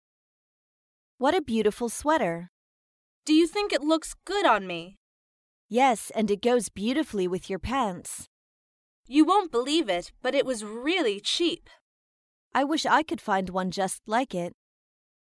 在线英语听力室高频英语口语对话 第125期:称赞着装(2)的听力文件下载,《高频英语口语对话》栏目包含了日常生活中经常使用的英语情景对话，是学习英语口语，能够帮助英语爱好者在听英语对话的过程中，积累英语口语习语知识，提高英语听说水平，并通过栏目中的中英文字幕和音频MP3文件，提高英语语感。